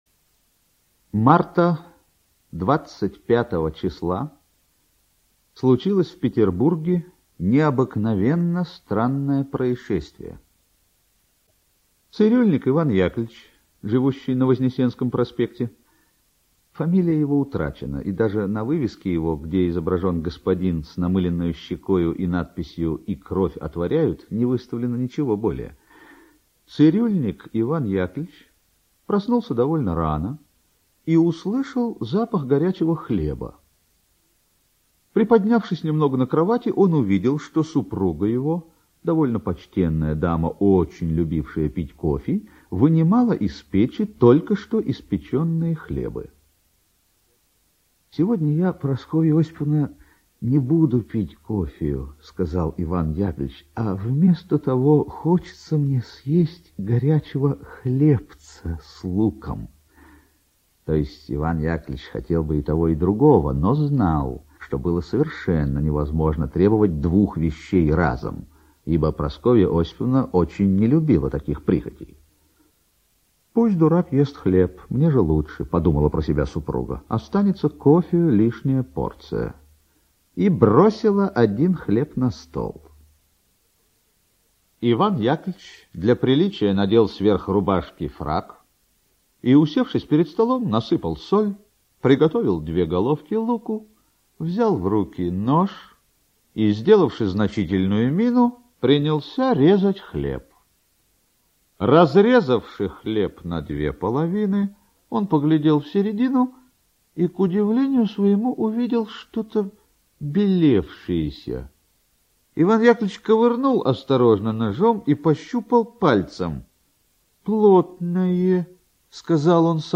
Нос - аудио повесть Николая Гоголя - слушать онлайн